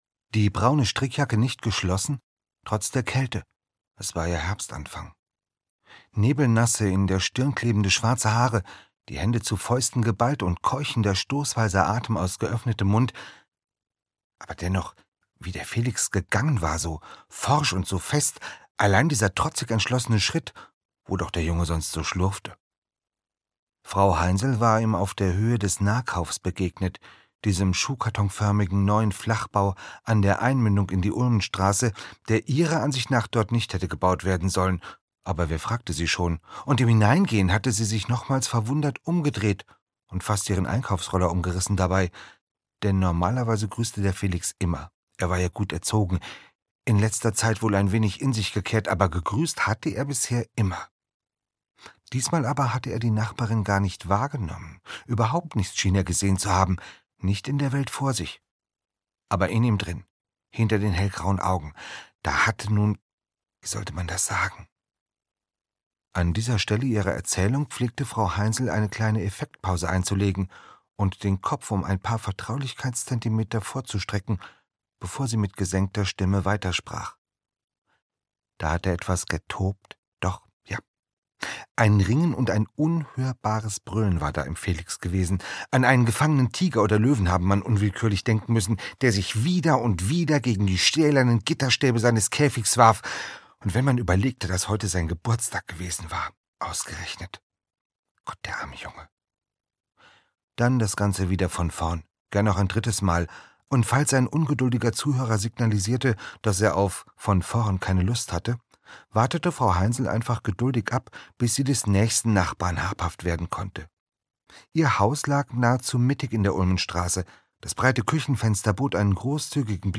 Anders - Andreas Steinhöfel - Hörbuch